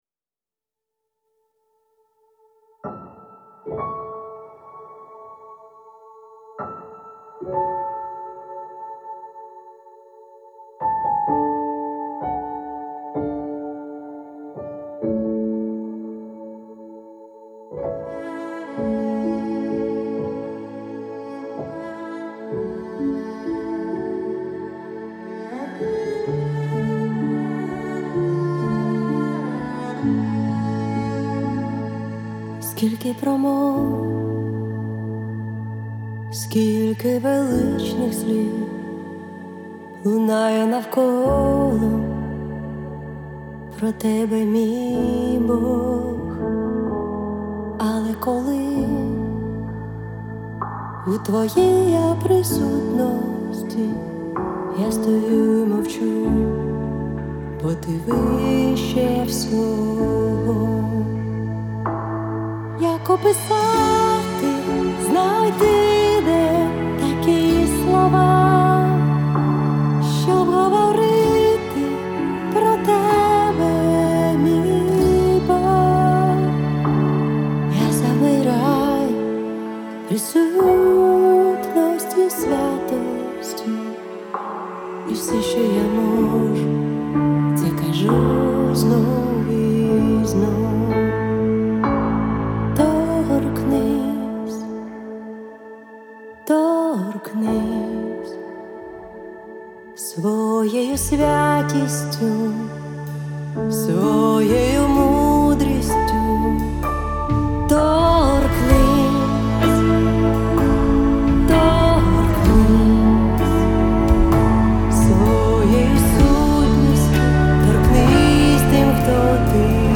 86 просмотров 128 прослушиваний 7 скачиваний BPM: 128